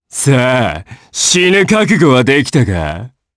Ezekiel-Vox_Skill2_jp.wav